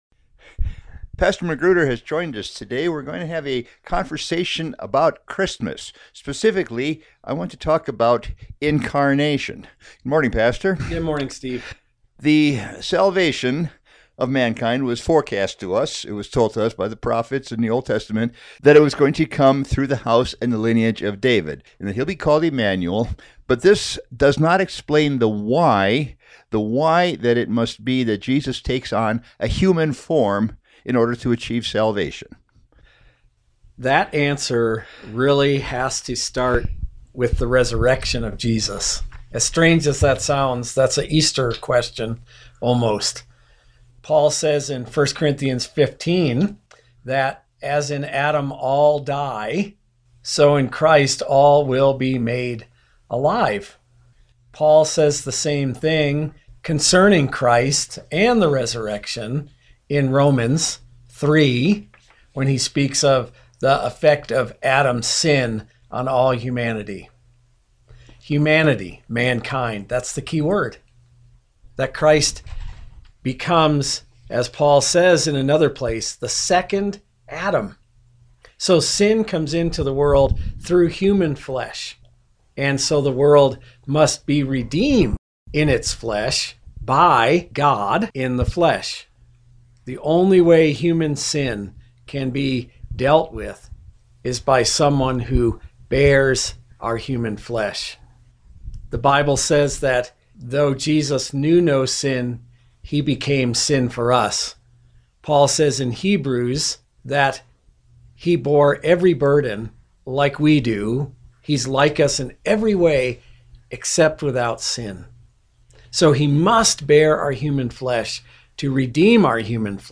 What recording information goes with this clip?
† The Nativity Of Our Lord † December 24, 2025